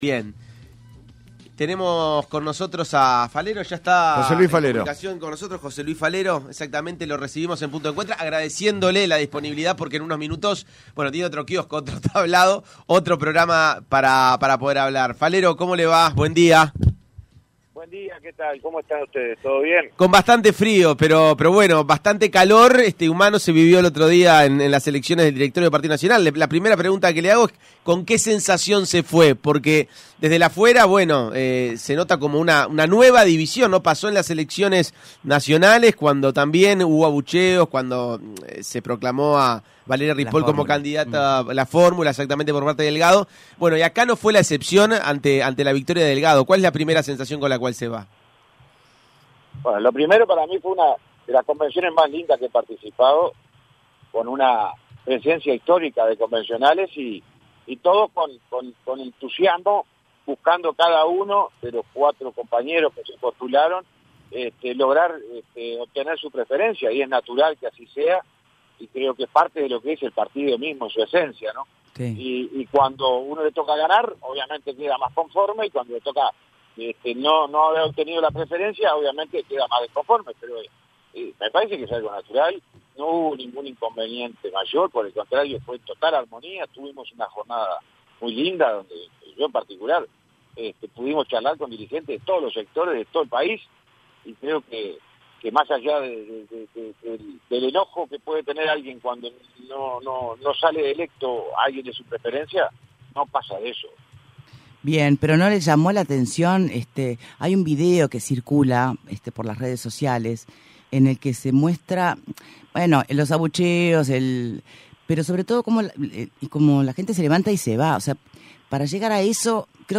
Escuche la entrevista completa aquí: En entrevista con Punto de Encuentro, el exministro de Transporte y senador del Partido Nacional, José Luis Falero se refirió a los abucheos que recibió Álvaro Delgado cuando se comunicó que había sido electo como presidente del directorio en la convención blanca.